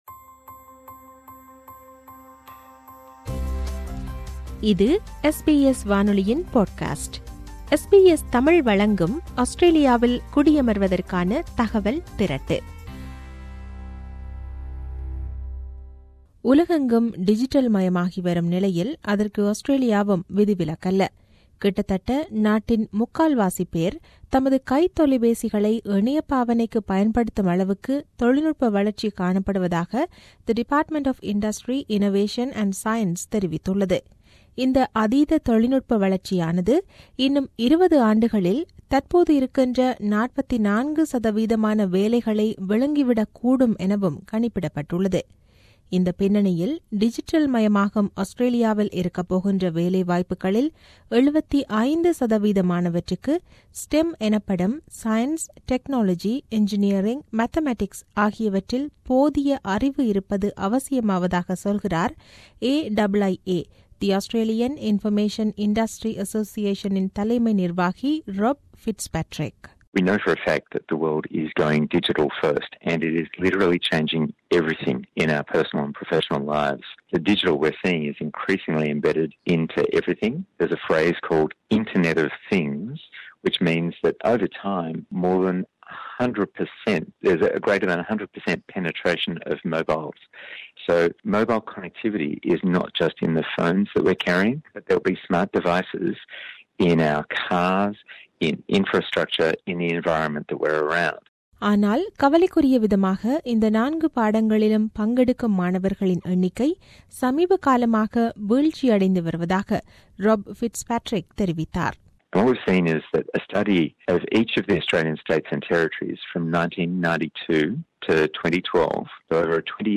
செய்தி விவரணத்தைத்